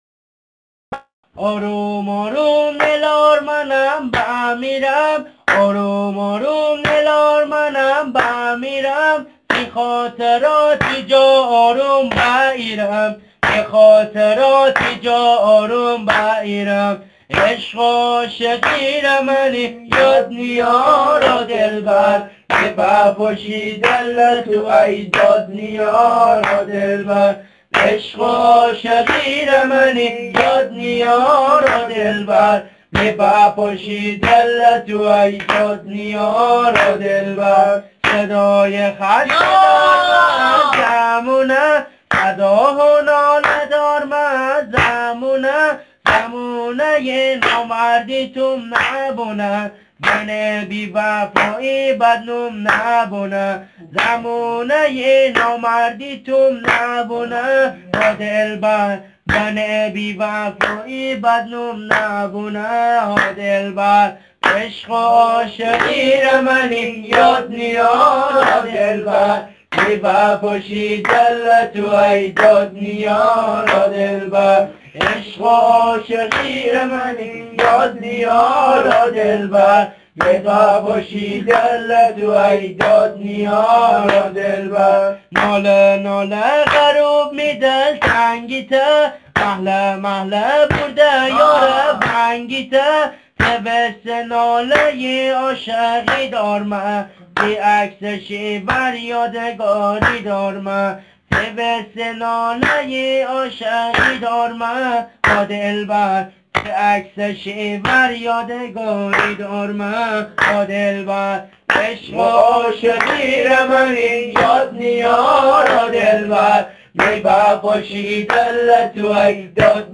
دانلود مازندرانی تک دست
آهنگ شاد